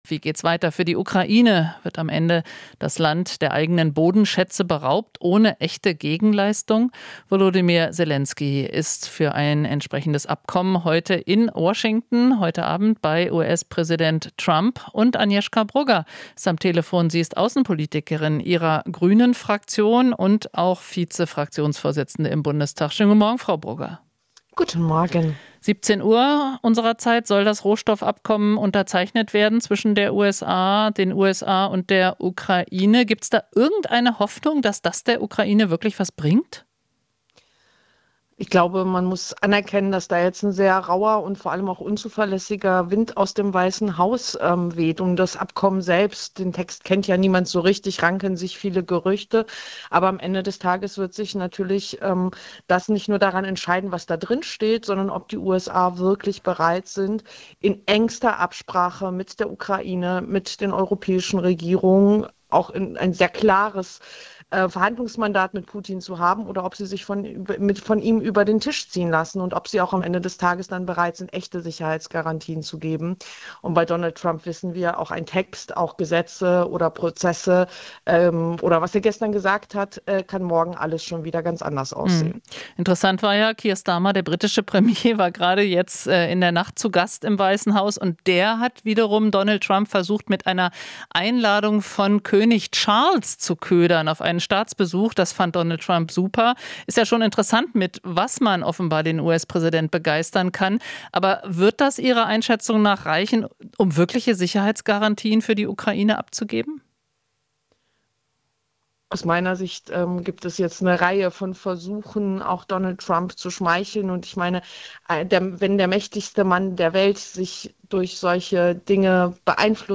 Interview - Brugger (Grüne): EU braucht klare Stimme und entschlossene Taten